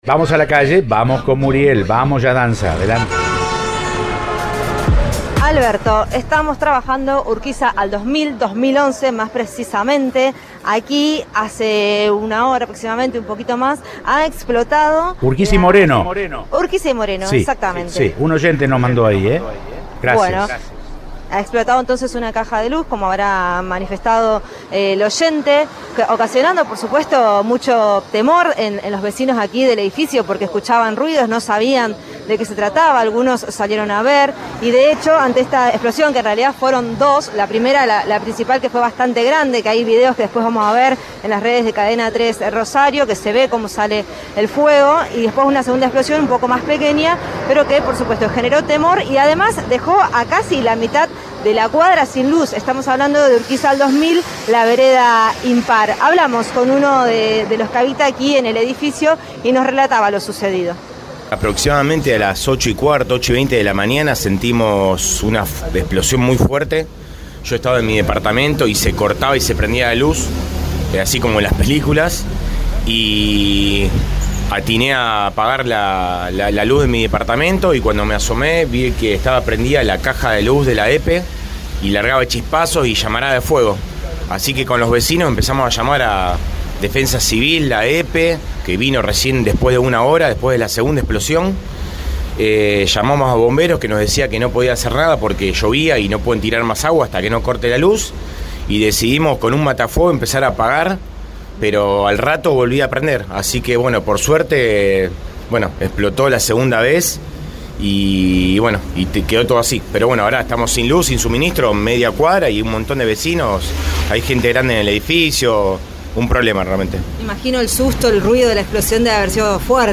Mientras esperan que vuelva la luz, un vecino habló con el móvil de Cadena 3 Rosario, en Siempre Juntos.